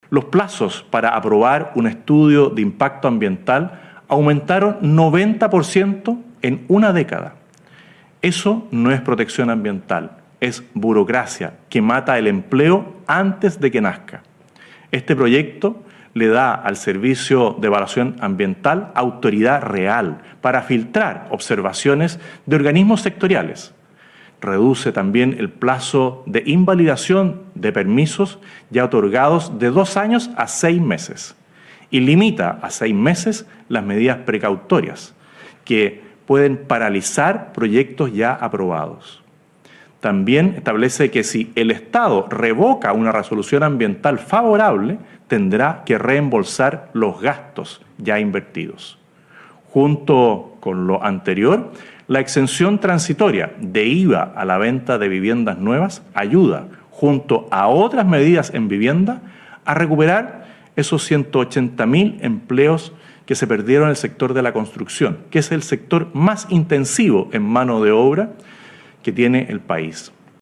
En su primera cadena nacional, el Presidente José Antonio Kast realizó un balance de su primer mes de gobierno y anunció el envío al Congreso del proyecto de ley de Reconstrucción y Desarrollo Económico y Social para “impulsar el crecimiento, el empleo y la seguridad en el país”.
cuna-02-cadena-nacional-Kast.mp3